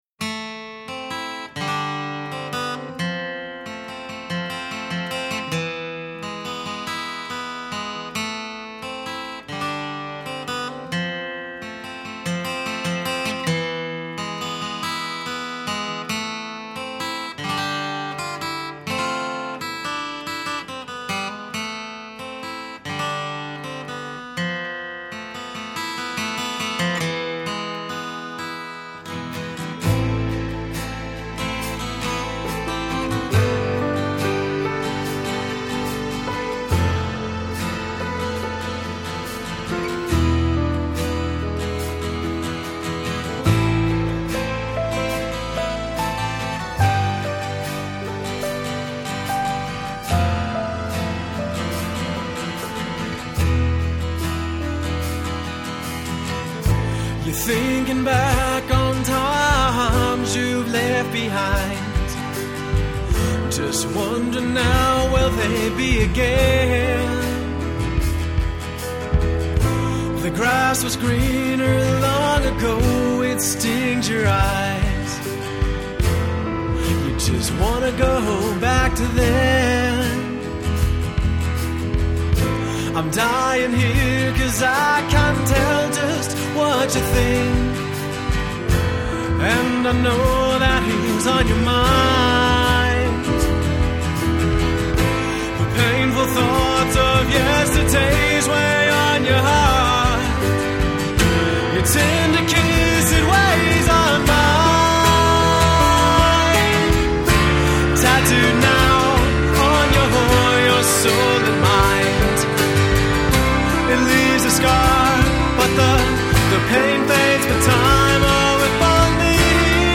Guitar, Vocals
Drums
Piano, Keyboards
Upright Bass